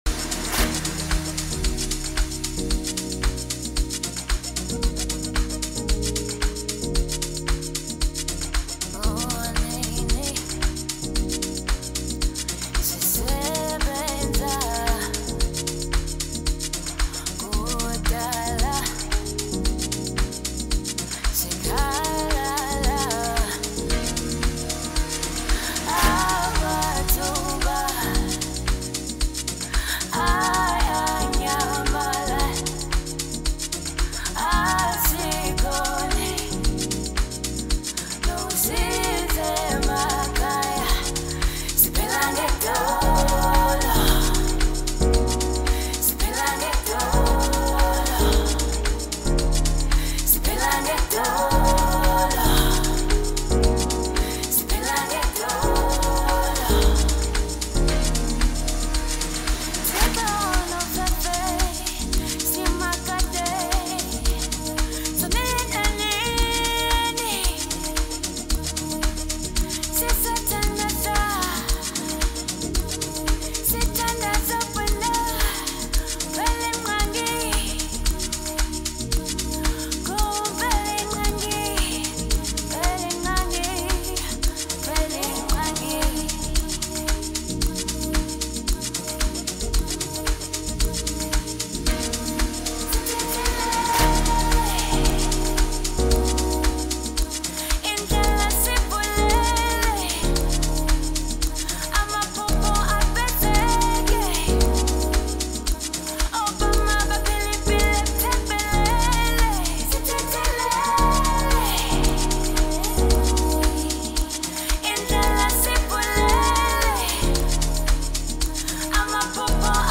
Home » Maskandi » Hip Hop